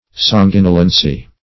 Search Result for " sanguinolency" : The Collaborative International Dictionary of English v.0.48: Sanguinolency \San*guin"o*len*cy\, n. The state of being sanguinolent, or bloody.